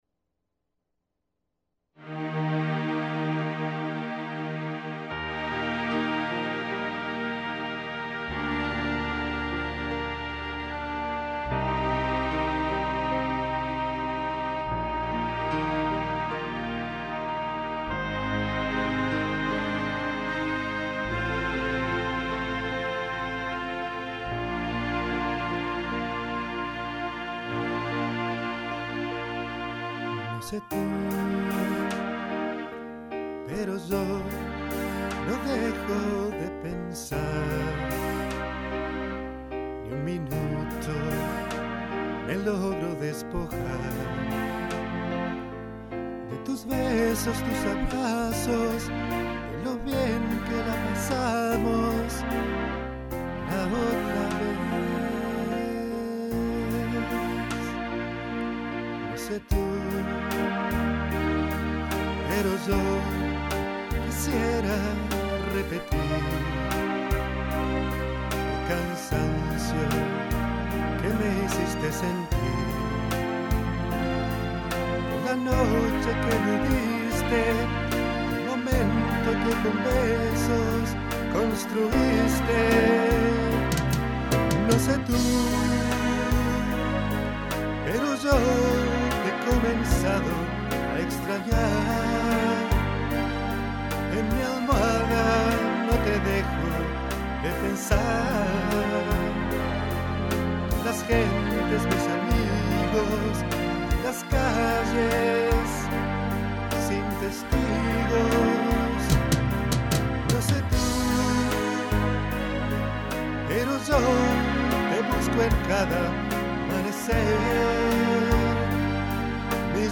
voz
teclados y MIDI
Grabado en agosto de 2003 en Saint Louis, Missouri.